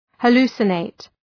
{hə’lu:sə,neıt}